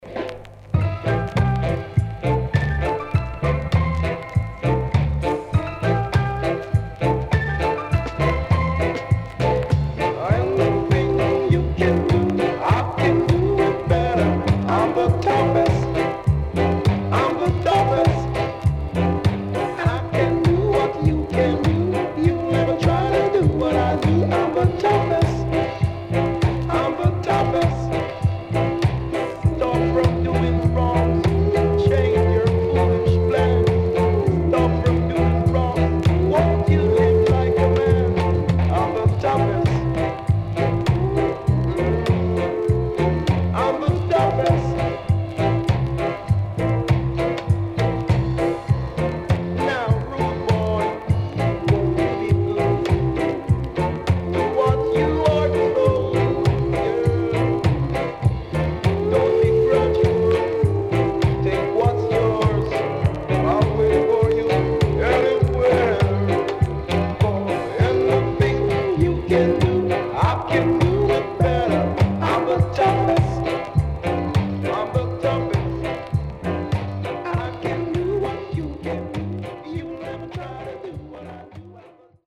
78年 Remix Take